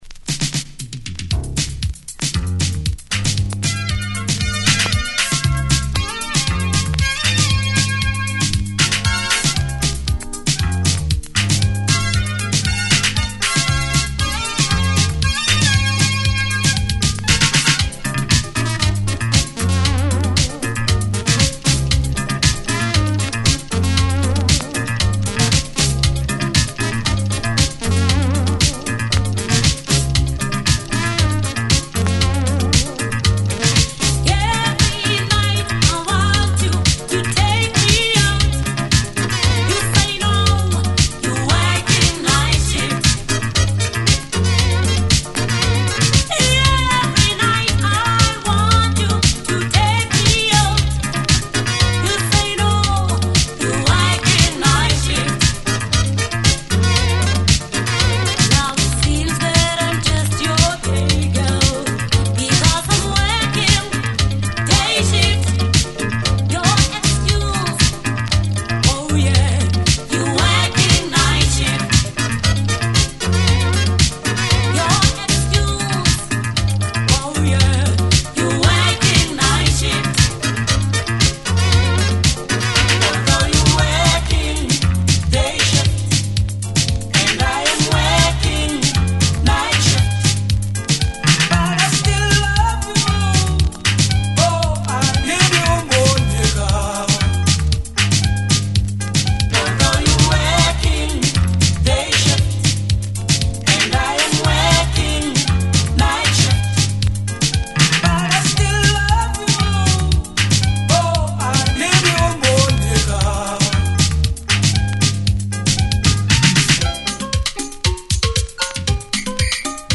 Synth African pop from SA. Yes Johannesburg shakedown!